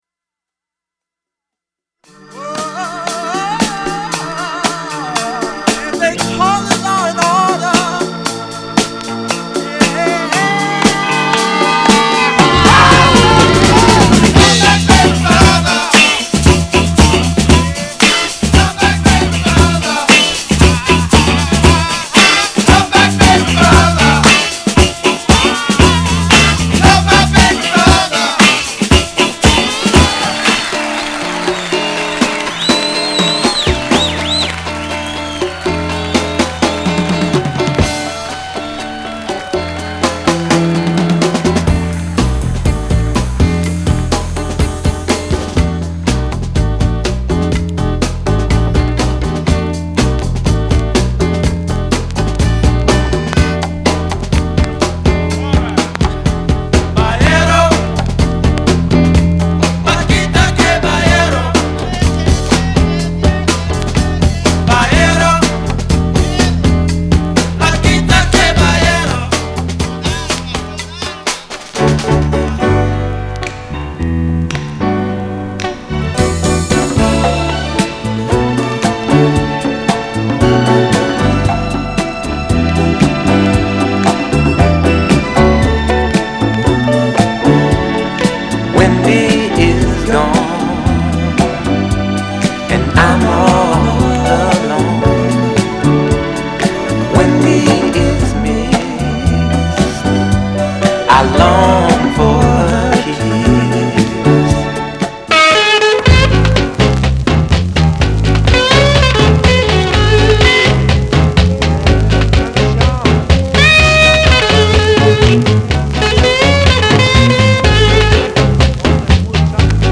category Blues